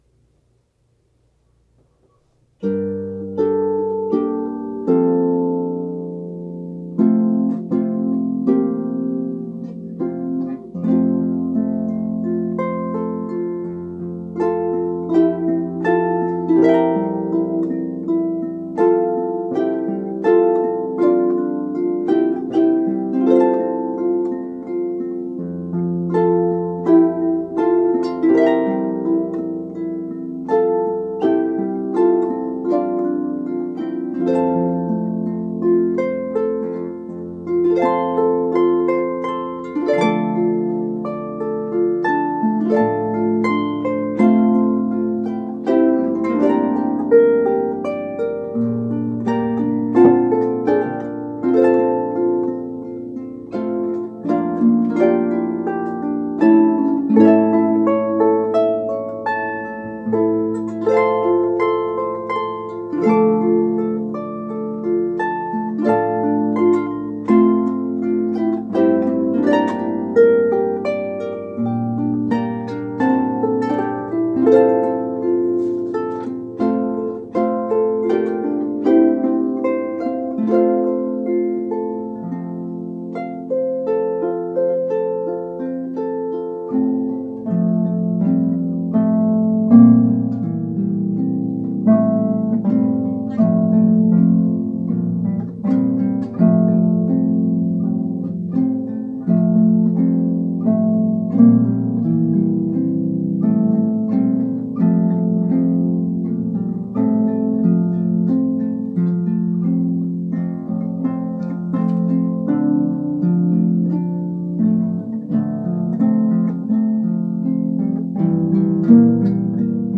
arranged for two lever or pedal harps